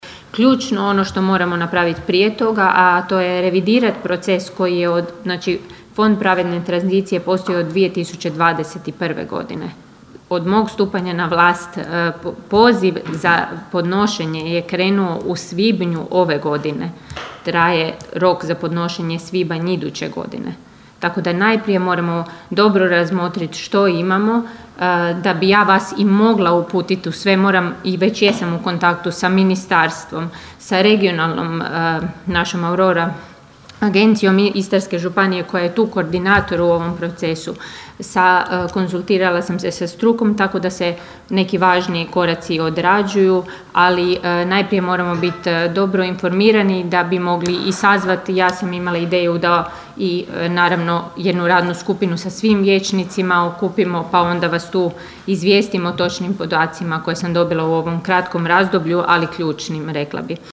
Na prošlotjednoj sjednici Općinskog vijeća Kršana dva su zanimljiva pitanja uputili vijećnik s Nezavisne liste Romana Carića Boris Rogić i nezavisni vijećnik Valdi Runko. Rogića je zanimalo što je s osnivanjem Radne skupine  za prijavu projekata na Fond za pravednu tranziciju.